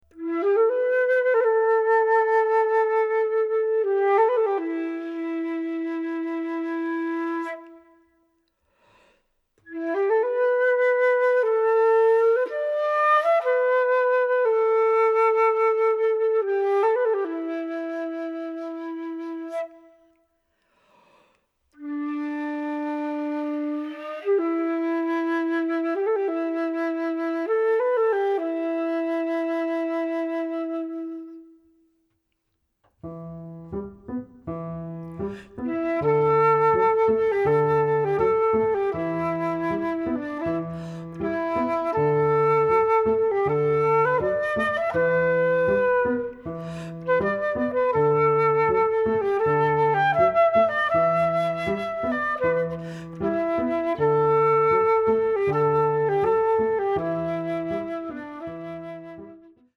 Pour flûte et piano